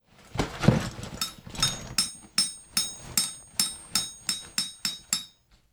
action_tentunpack_0.ogg